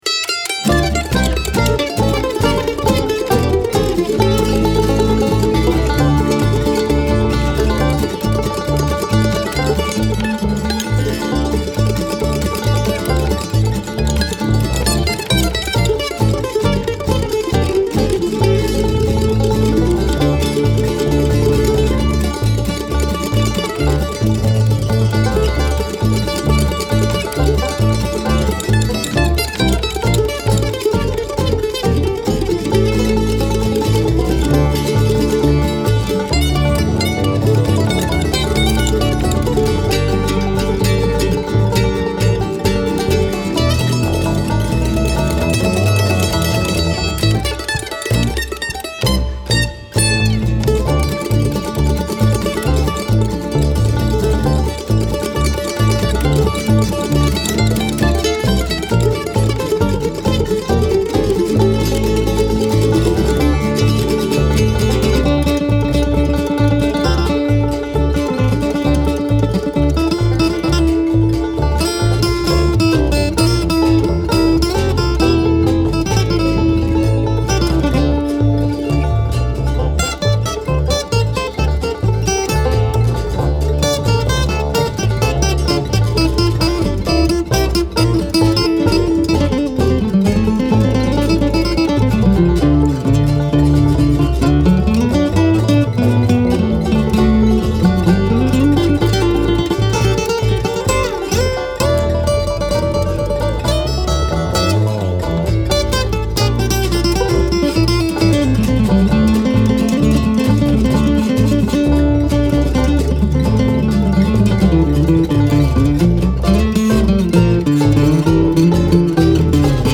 progressive wood-tone music